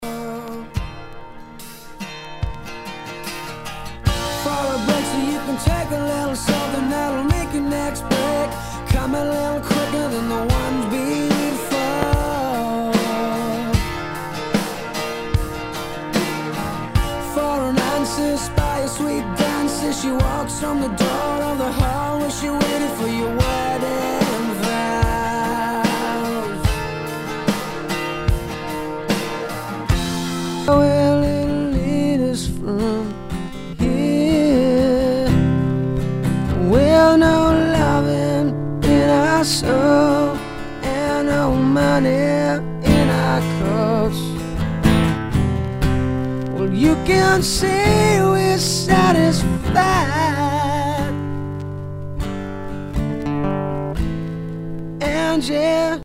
ROCK/POPS/INDIE
ナイス！インディー・ロック！